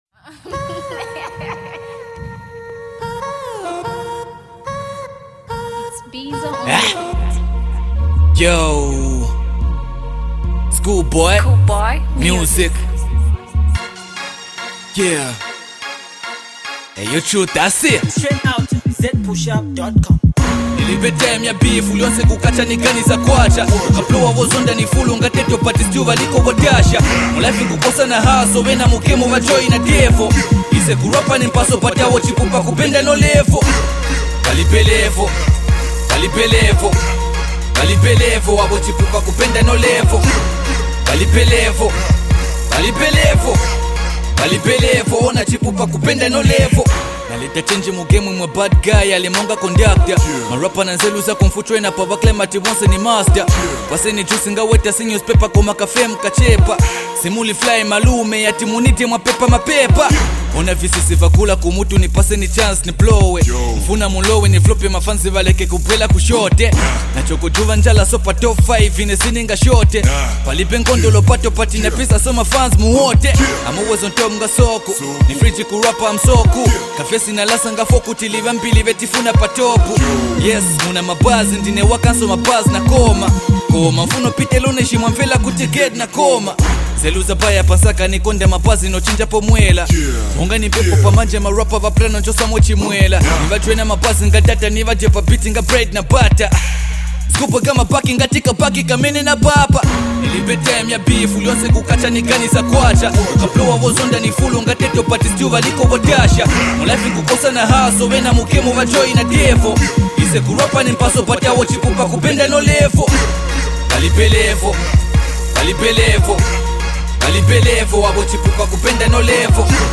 a dope hiphop jam